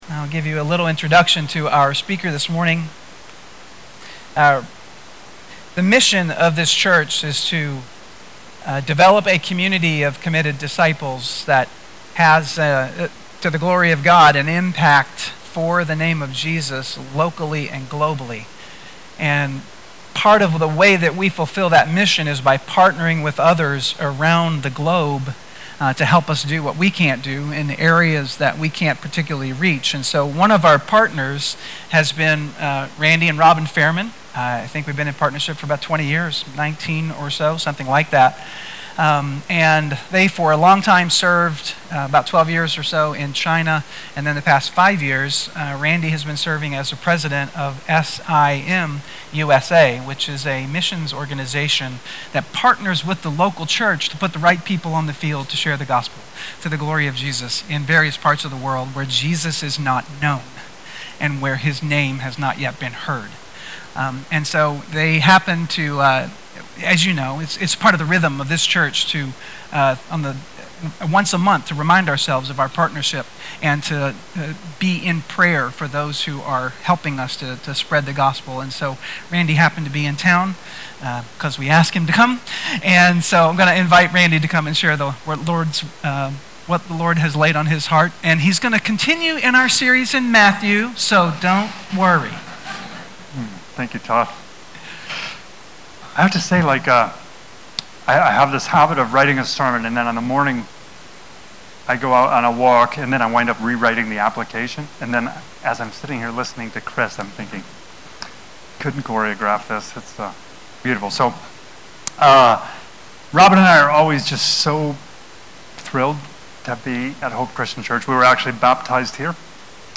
Sermons | Hope Christian Church